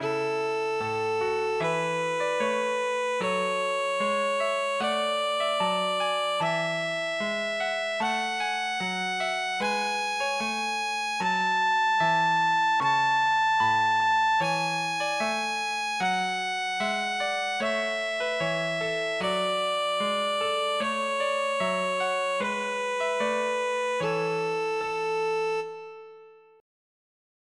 Kleines Übungsstück 1 in A-Dur für Violine
Violine mit Klavierbegleitung
Digitalpiano Casio CDP-130
Yamaha Silent Violin SV150 mit Evah Pirazzi Gold